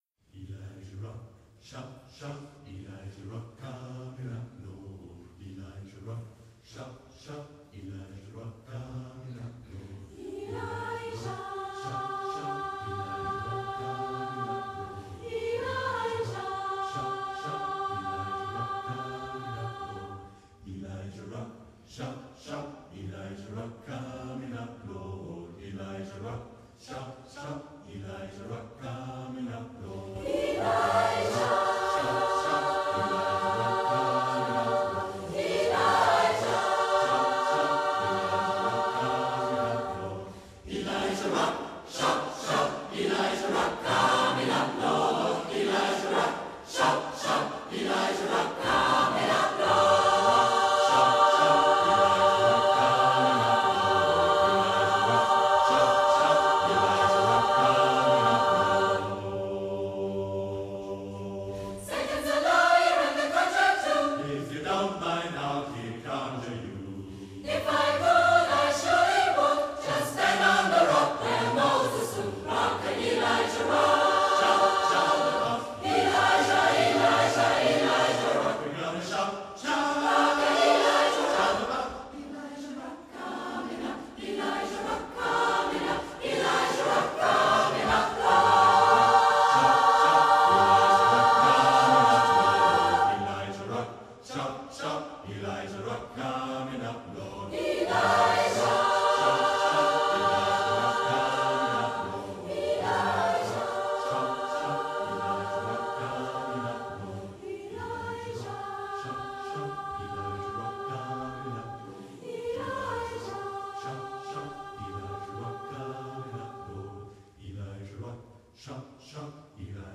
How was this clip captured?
Mitschnitte von Konzerten oder Proben als mp3